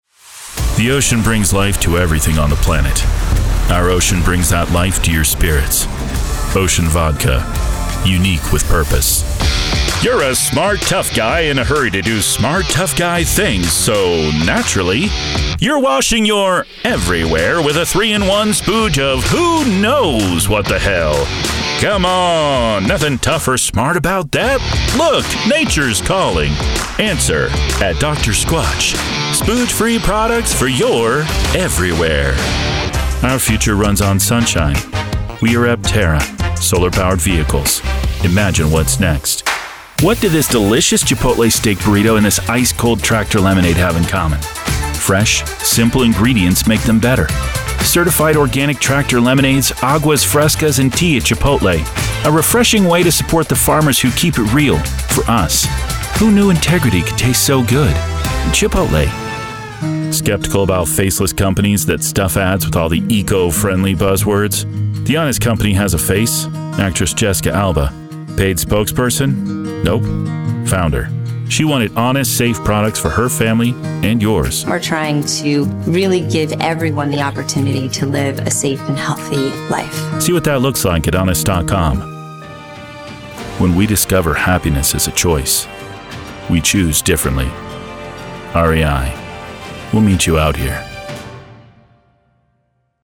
Versatile Voice Actor from Engaging & Believable Accents to Warm & Authentic Everyman
Commercial Demo
American Southwestern / Western / Texas, American South, American New York, Transatlantic, British (General), Irish (General)
Young Adult
Middle Aged